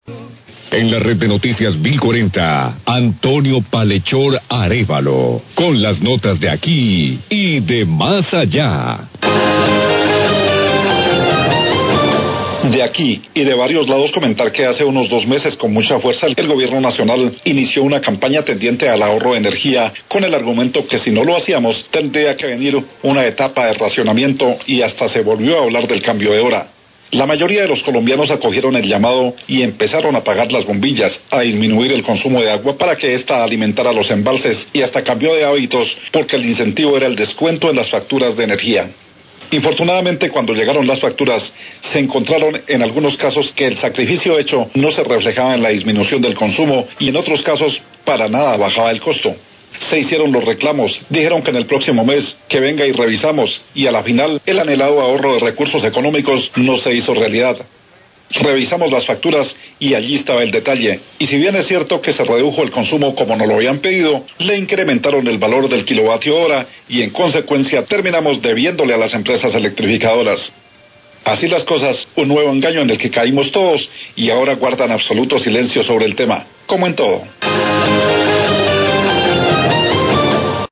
Radio
columna de opinión